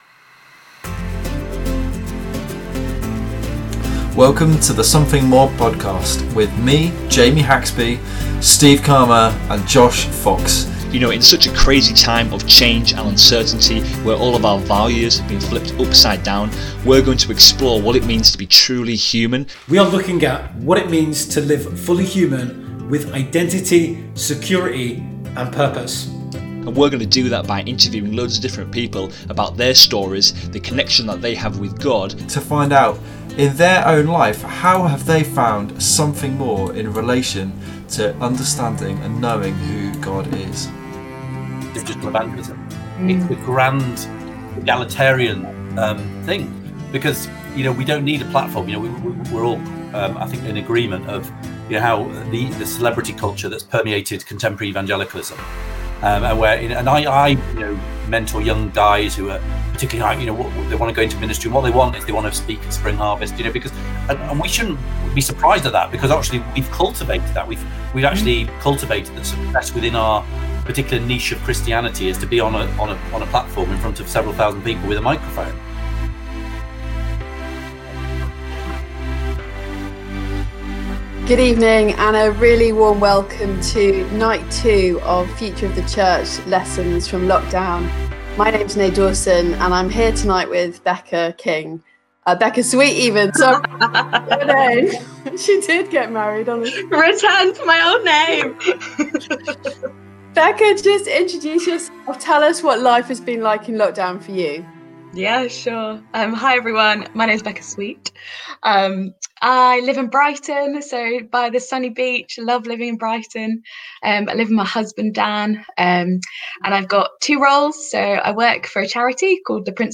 discussing together the future of the church after lockdown